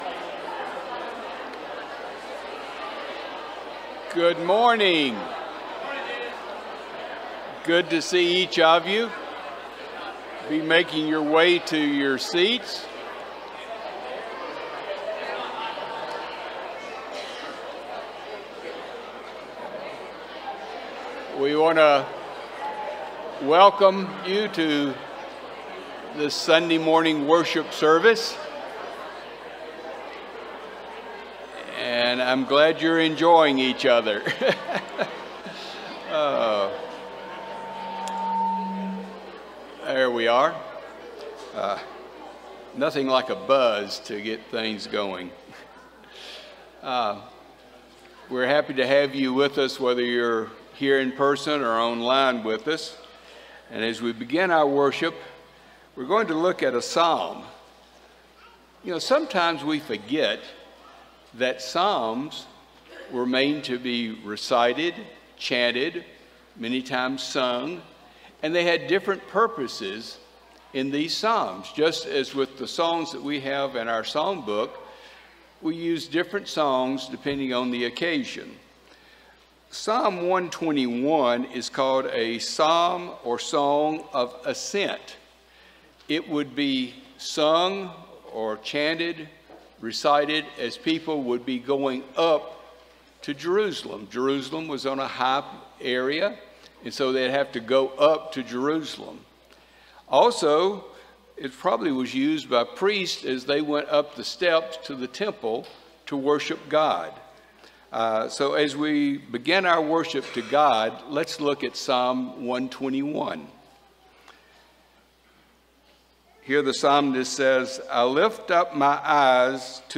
Series: Sunday AM Service